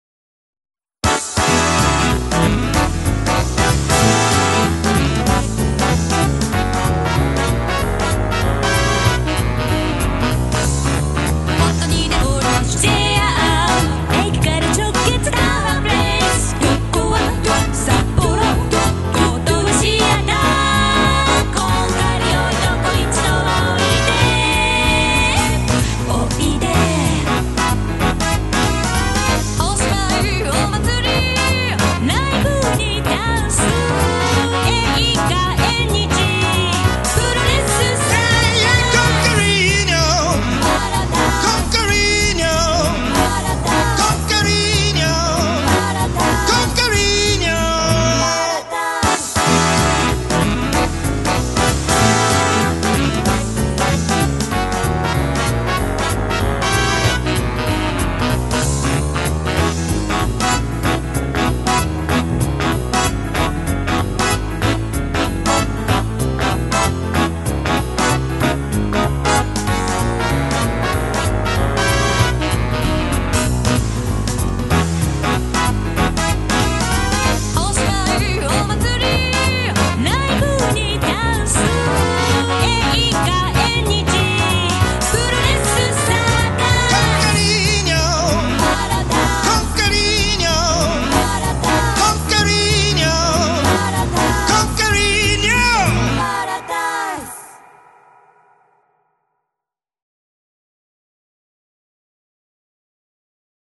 使ったキーボードはKORGのN364という製品で、発売年を調べると1996年。
20世紀の製品ですし、KORGの最上位機種といったものでも勿論ありませんが、バリトン、アルト、ソプラノ、トロンボーン、トランペットというホーンセクションのシミュレーション音源は、その当時なりに良く出来ているなーと思って一つ一つ地味に重ねていった作業を良く覚えています。